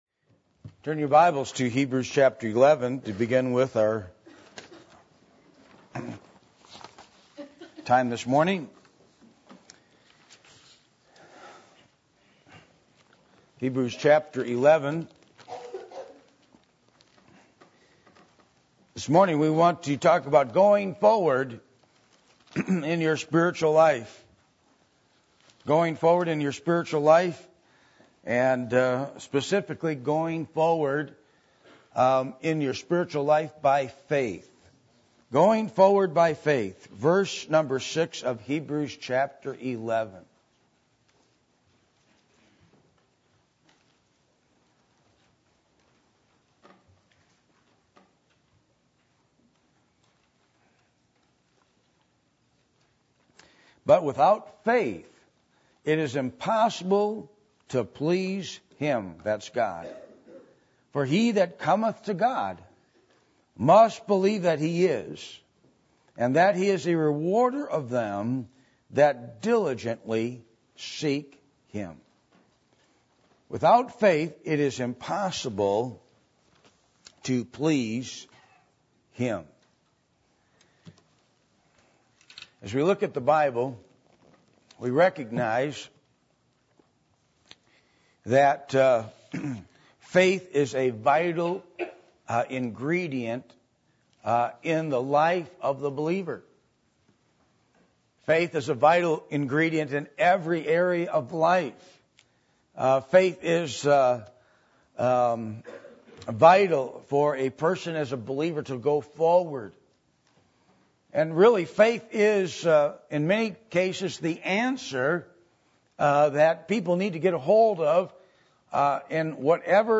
Hebrews 11:6-7 Service Type: Sunday Morning %todo_render% « The Godly Seed